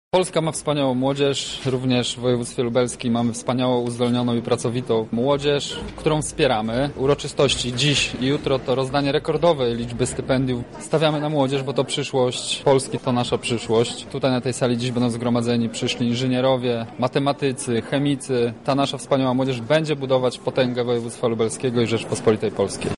O tym, dlaczego warto wspierać młodych ludzi, mówi Wicemarszałek Województwa Lubelskiego Michał Mulawa: